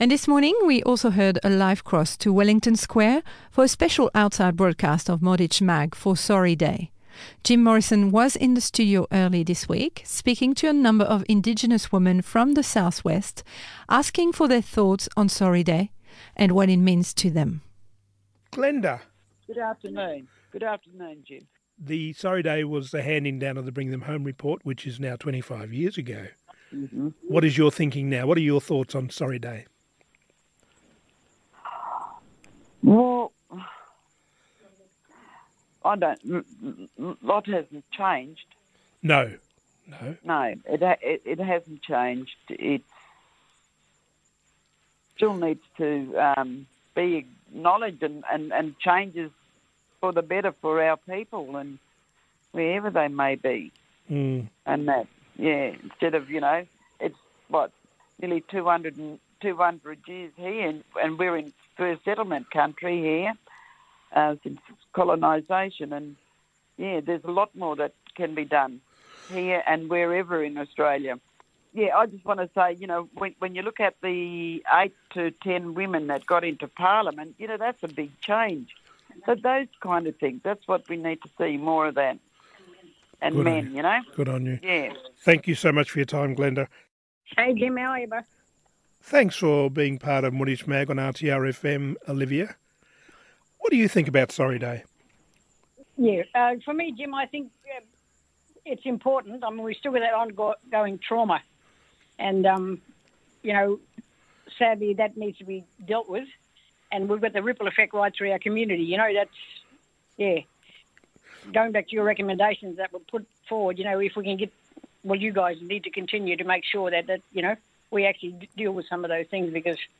Part Two switches back to the studio